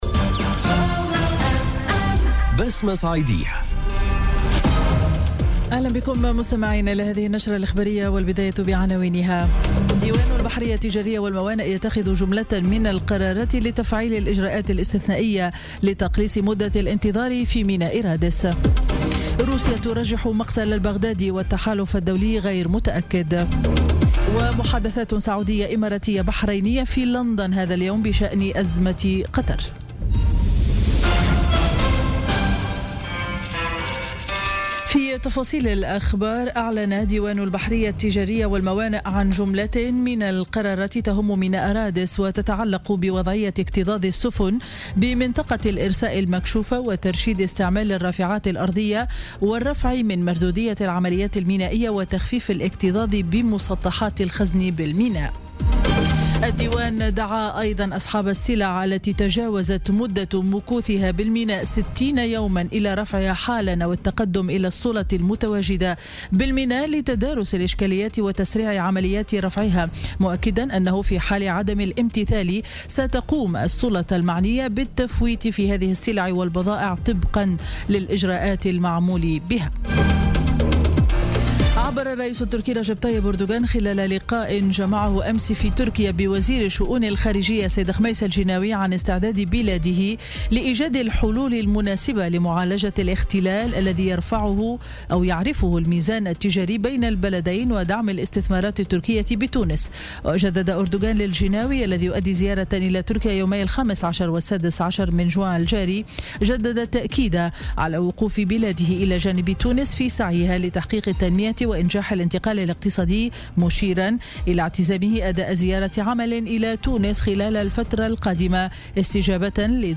نشرة أخبار منتصف النهار ليوم الجمعة 16 جوان 2017